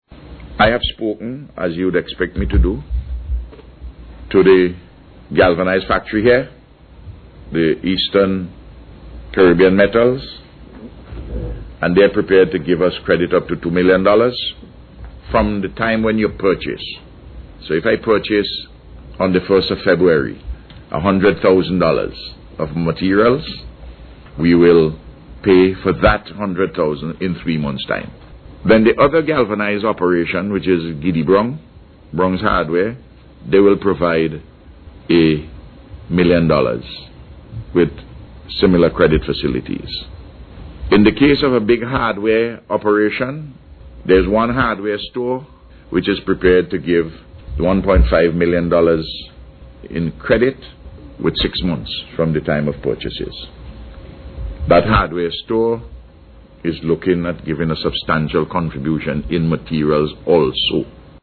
This was announced by Prime Minister Dr. Ralph Gonsalves at a Media Conference this morning.